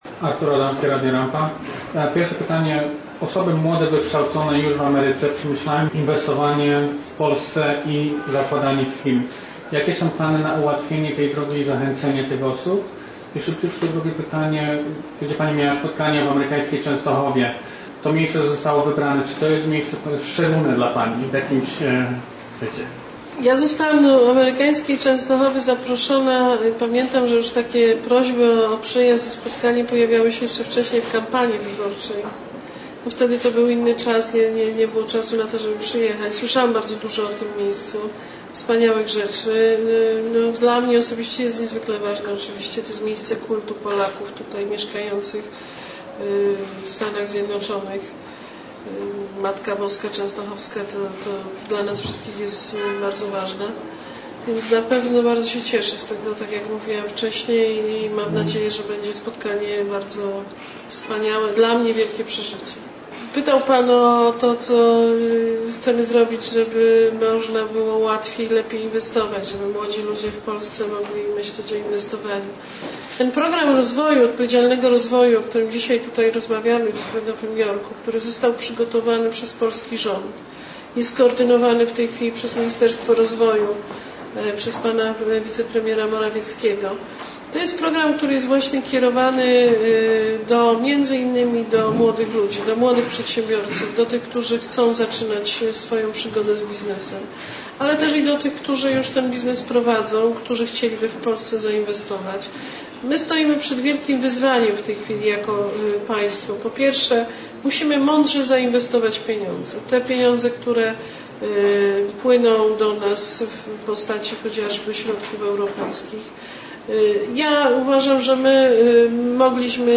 21 kwietnia, podczas pierwszego dnia oficjalnej wizyty Beaty Szydło w Nowym Jorku, premier Polski spotkała się z mediami polonijnymi, podczas konferencji prasowej w Konsulacie RP na Manhattanie.